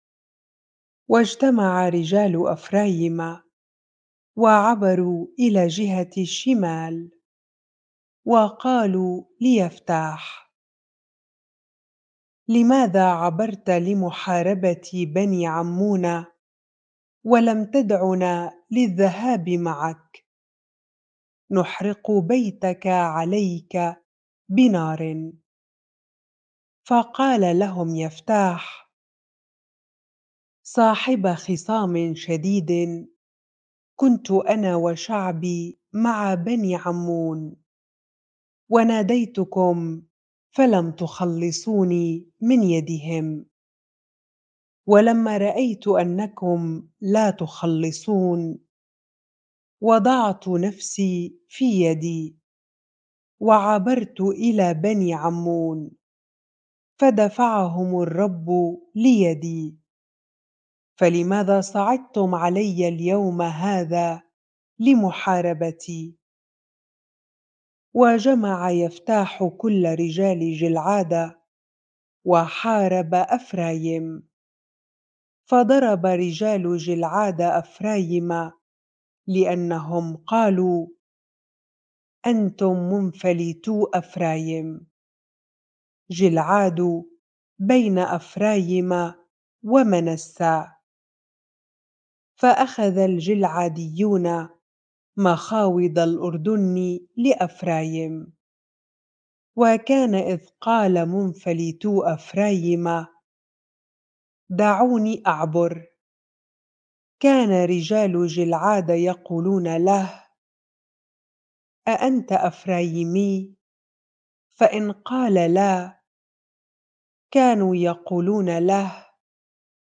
bible-reading-Judges 12 ar